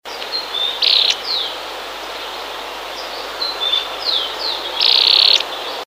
Anairetes-Parulus-1-Cachudito.mp3